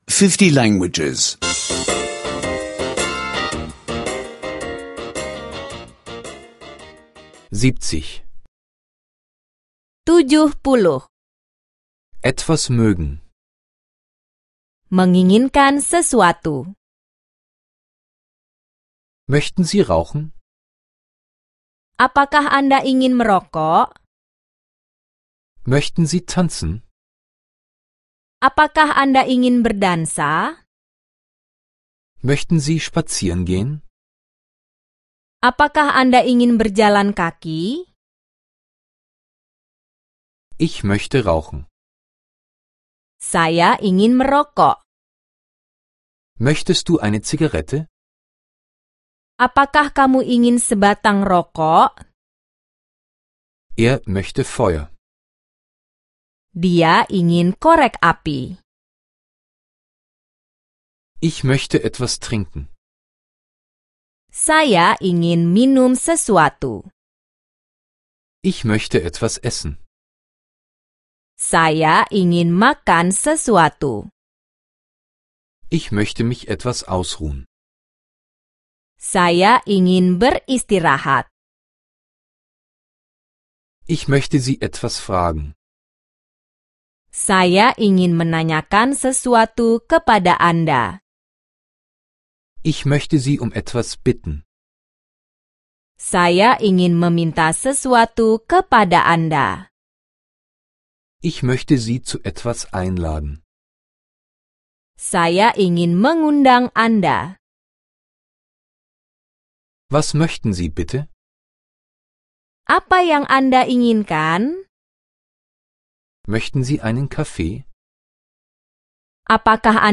Indonesisch Audio-Lektionen, die Sie kostenlos online anhören können.